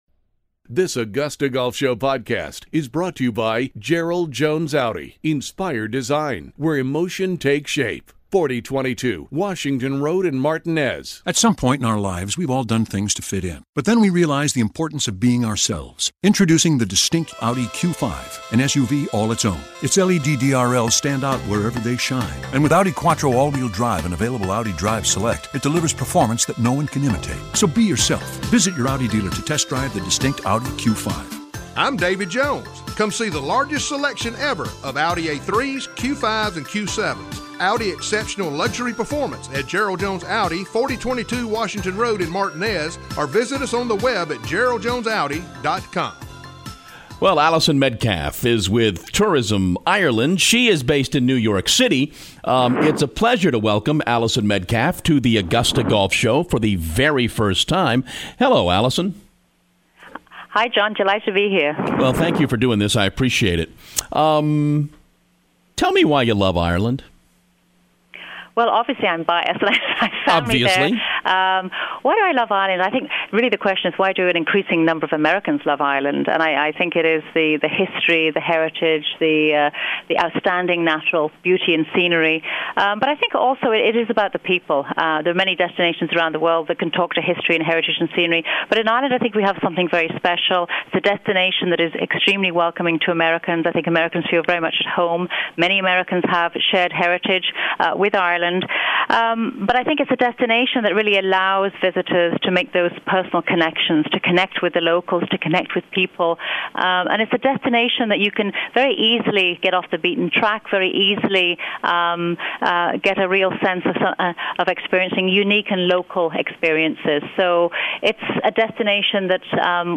The AGS Interview